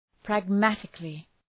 Προφορά
{præg’mætıklı} (Επίρρημα) ● πρακτικώς ● θετικώς